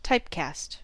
Ääntäminen
Synonyymit cast Ääntäminen US RP : IPA : /ˈtaɪp.kɑːst/ US : IPA : /ˈtaɪp.kæst/ Haettu sana löytyi näillä lähdekielillä: englanti Käännöksiä ei löytynyt valitulle kohdekielelle.